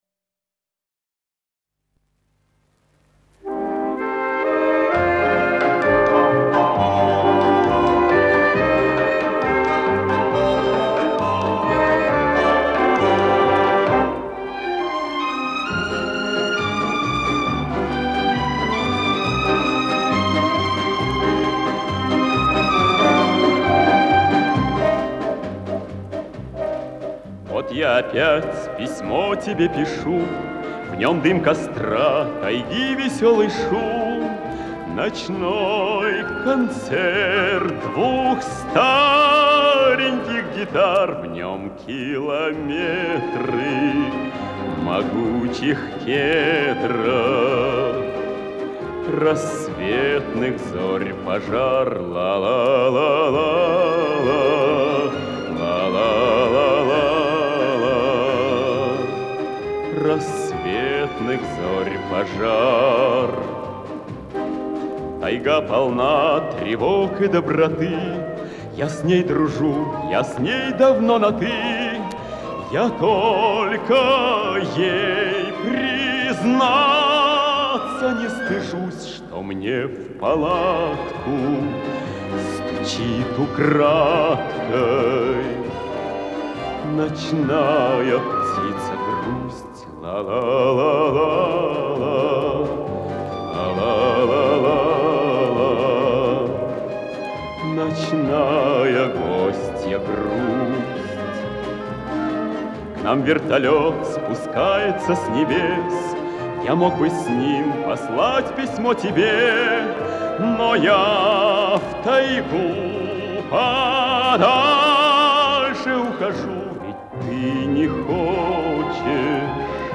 советский и российский певец (баритон).